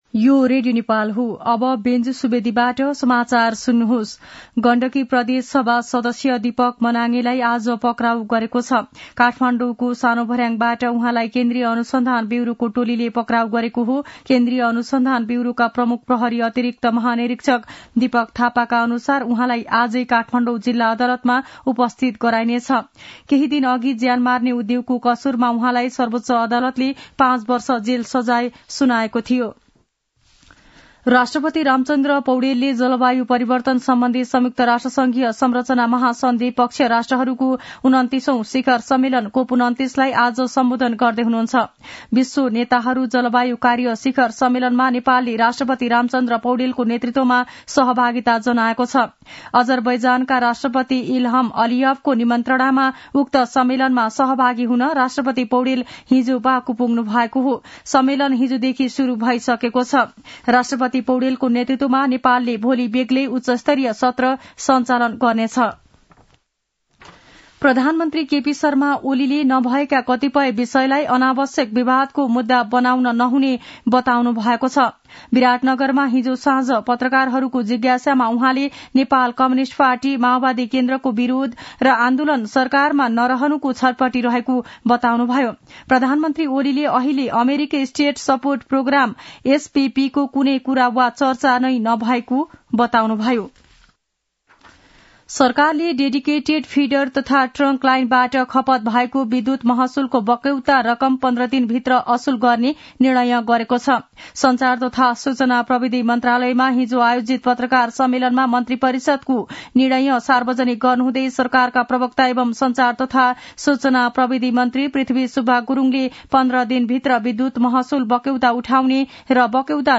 मध्यान्ह १२ बजेको नेपाली समाचार : २८ कार्तिक , २०८१